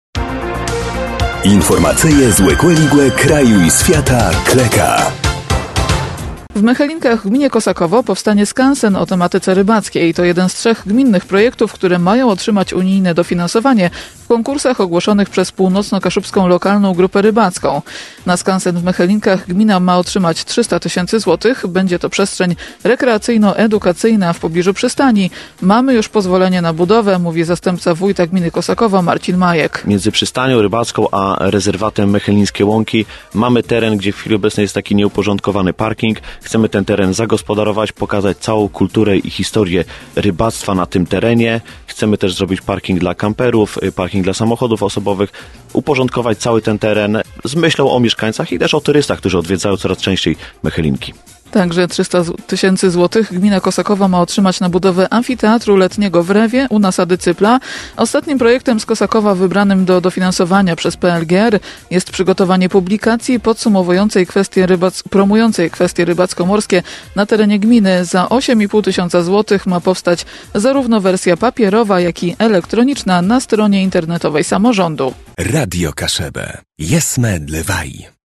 – Mamy już pozwolenie na budowę – mówi zastępca wójta gminy Kosakowo Marcin Majek.